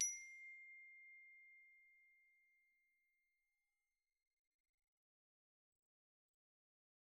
glock_medium_C6.wav